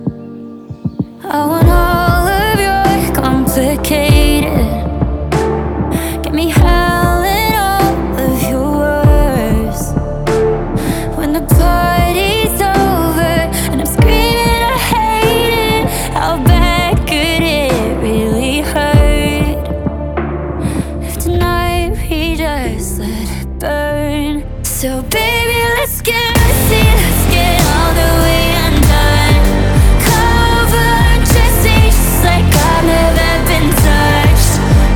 Скачать припев
2025-05-08 Жанр: Поп музыка Длительность